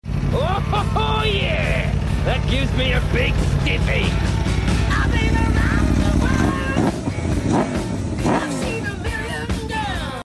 This bike sounds so angry sound effects free download
This bike sounds so angry 😤⚡ Aprilia Tuono V4